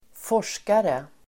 Uttal: [²f'år_s:kare]
forskare.mp3